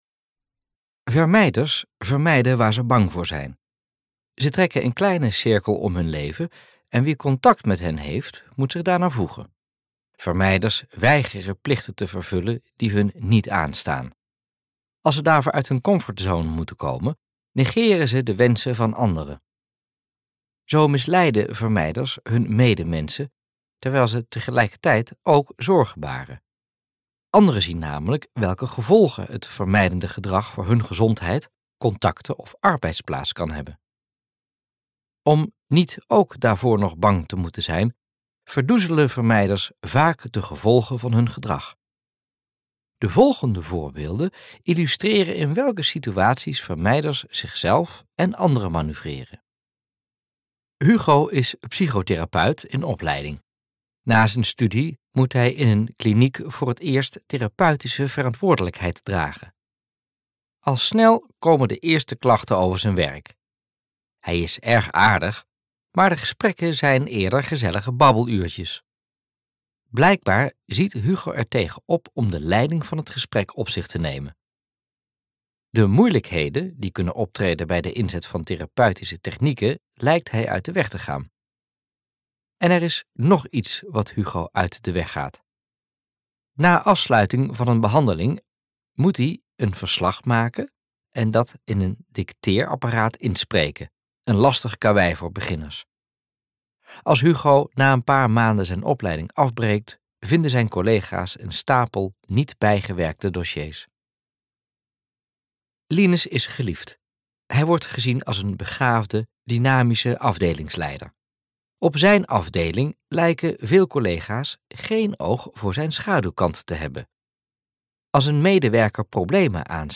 Luisterboek : MP3 download